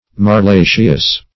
Marlaceous \Mar*la"ceous\